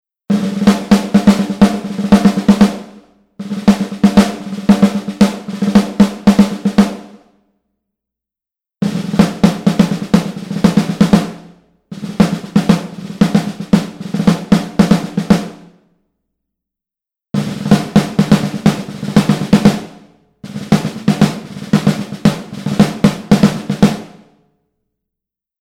Recorded On Analog Tape At Electrical Audio
The samples were captured using high-quality digital converters, then passed through the immaculately-maintained Studer A820 tape machine at Electrical Audio for authentic tape character.
The entire drum kit was captured in stunning detail with 21 individual microphones, including two sets of stereo overheads and four room mic options.
Raw Snares
KB2_Raw_Snares.mp3